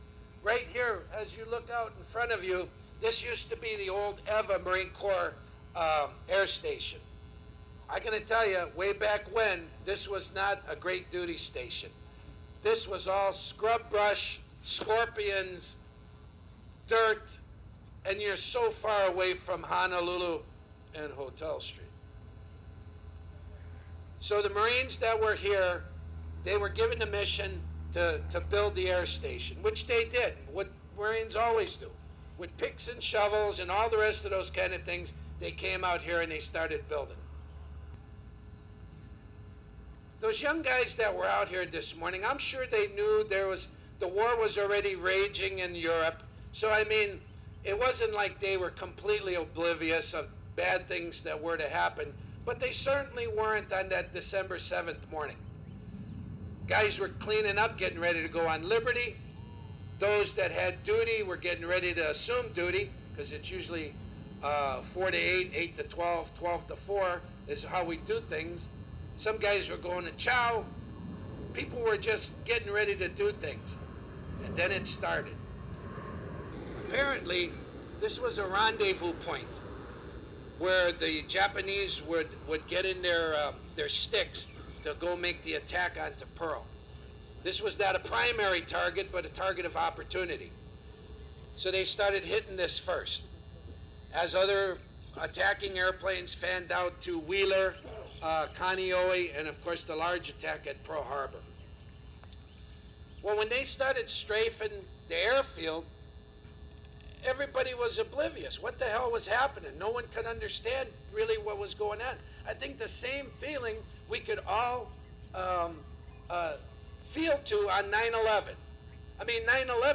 But I headed down to Barbers Point Golf Course for a little-heralded ceremony conducted by a Navy League volunteer and attended by a few old Marines.
Listen to the audio clip below for most of the short ceremony, delivered as only a rusty old veteran can.  I especially like the reference to this place being a long way from Honolulu….and Hotel Street, and also how the greatest generation was “just a little bit older than them young people standing right there” (i.e., the junior ROTC high schoolers).